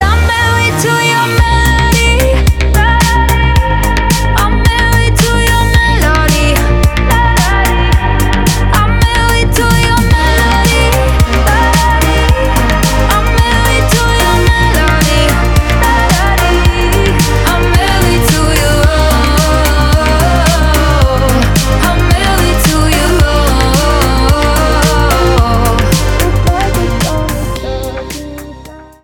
• Качество: 320, Stereo
поп
мощные
Electronic
красивый женский голос
Классный танцевальный рингтон